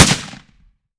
7Mag Bolt Action Rifle - Gunshot B 004.wav